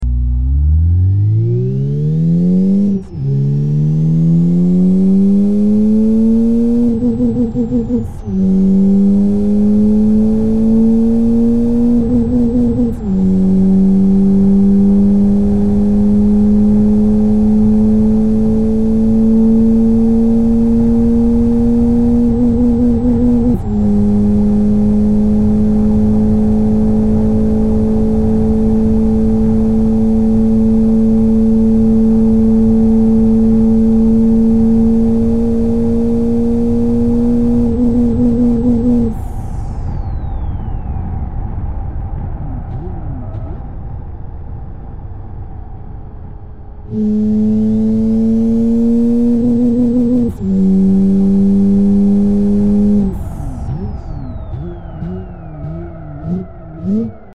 Rev Limiter?
I mean, like the rev bounces when it hits high revs.
made with the edit_eng and some fast clicking
bouncy.mp3 - 896.3 KB - 349 views